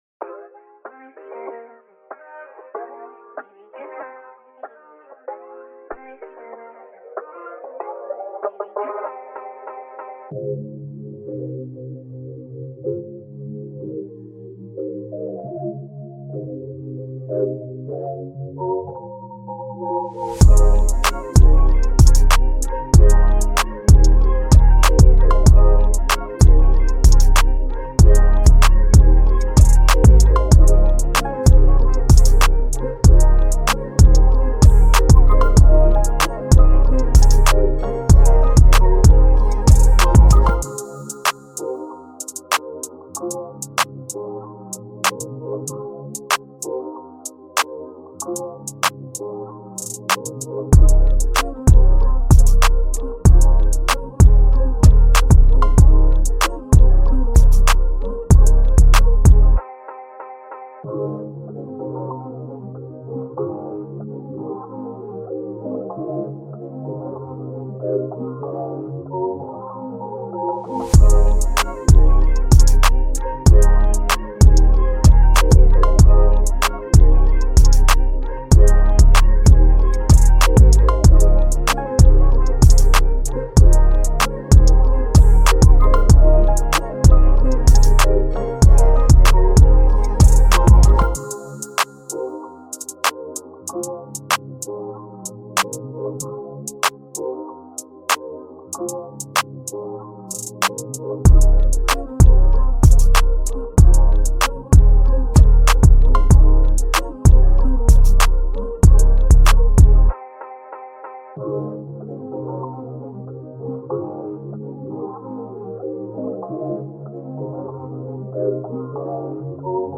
Here's the official instrumental of